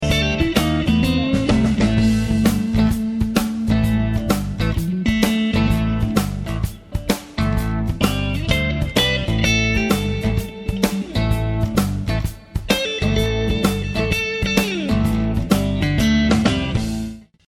JIM MESSINA MP3 FAT HEAD Solo sample
After I heard the Fender Hot Rod amp with your mics, I erased every guitar part on the track and started over.
The FAT HEAD warm full-bodied signature and increased sensitivity is what you would expect and demand from a professional ribbon microphone and the FAT HEAD delivers.
JIM_Messina_FH_Solo.mp3